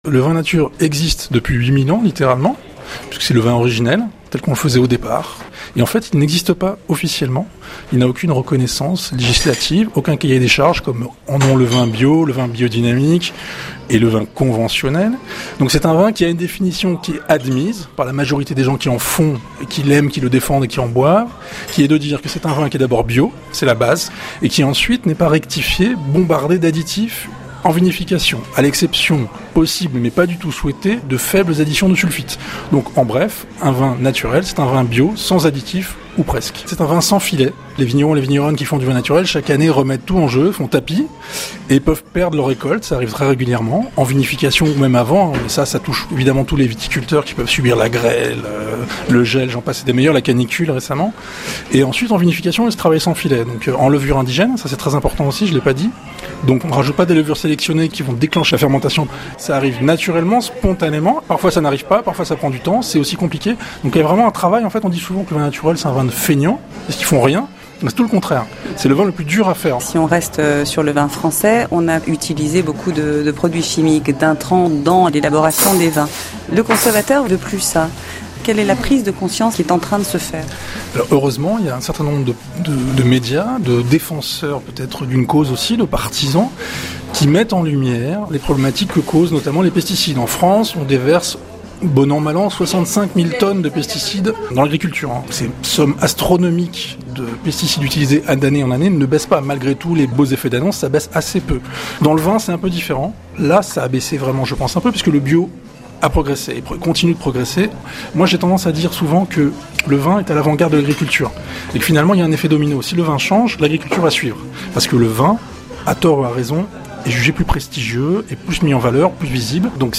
Aidez vos apprenant(e)s à acquérir les compétences pour savoir proposer un vin à leur client en écoutant l'interview de ce passionné d'oenologie qui nous parle de vin natuel.